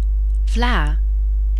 kiejtése) holland tejtermék, édes ivópuding.
NL-vla.ogg